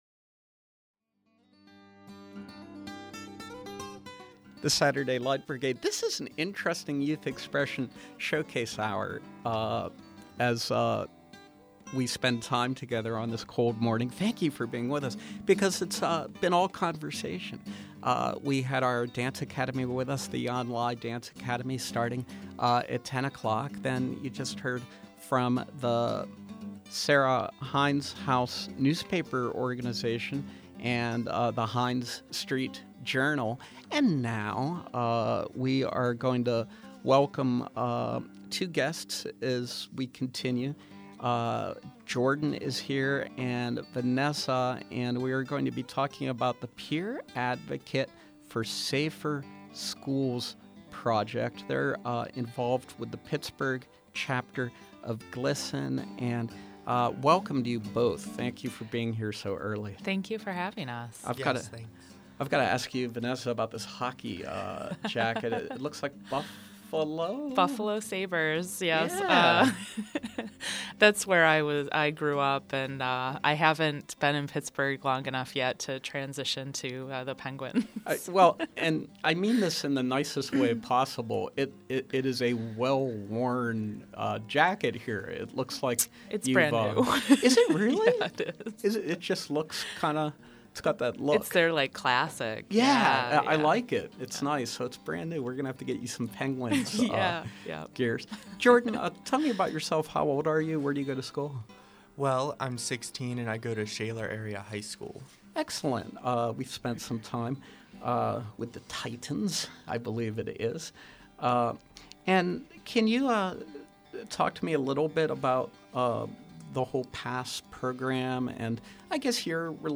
From 1/25/14: Discussion of the PASS (Peer Advocate for Safer Schools) Project with members of the Pittsburgh Chapter of GLSEN